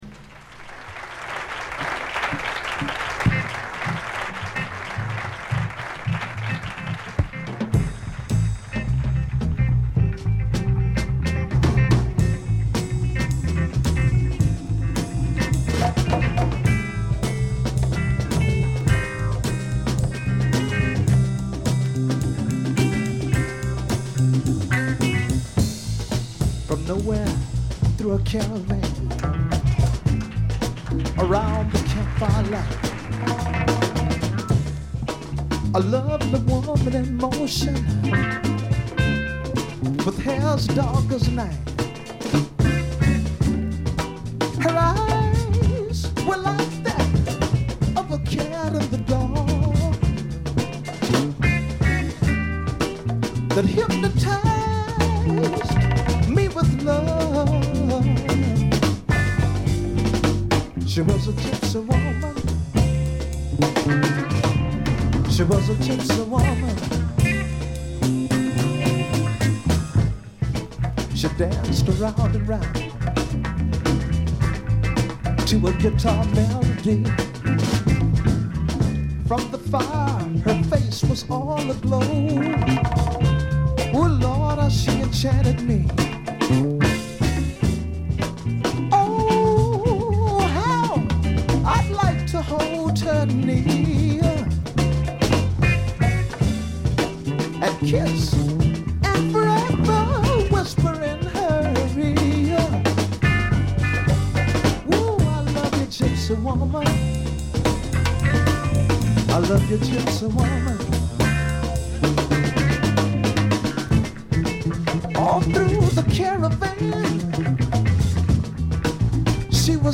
部分試聴ですがほとんどノイズ感無し。極めて良好に鑑賞できると思います。実際の音源を参考にしてください。
試聴曲は現品からの取り込み音源です。
Bongos, Congas, Percussion [Tumbas]